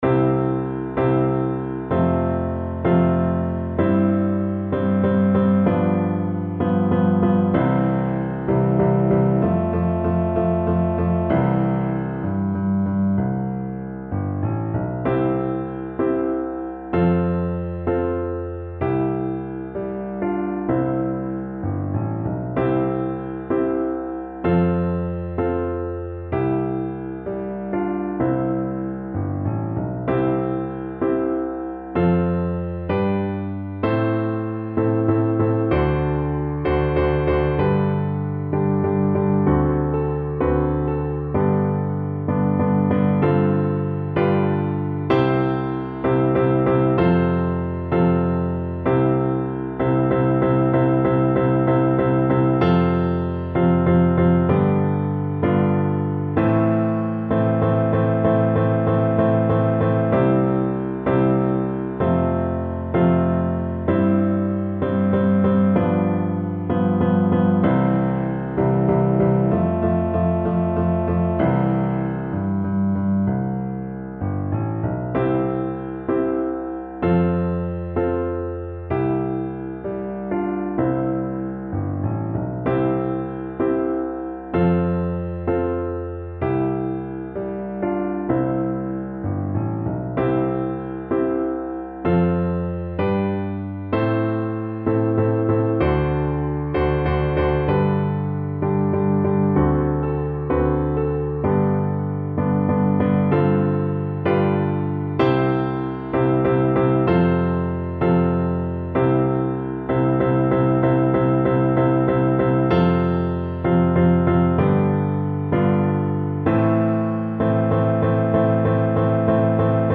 Voice version
4/4 (View more 4/4 Music)
Moderately slow with determination (=90)